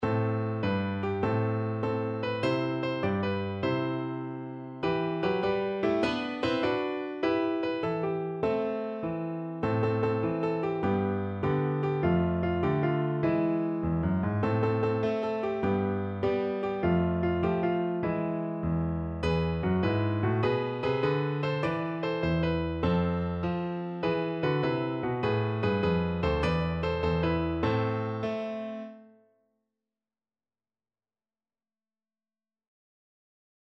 Free Sheet music for Piano
No parts available for this pieces as it is for solo piano.
Traditional Music of unknown author.
A minor (Sounding Pitch) (View more A minor Music for Piano )
6/8 (View more 6/8 Music)
With energy .=c.100
Piano  (View more Intermediate Piano Music)
Classical (View more Classical Piano Music)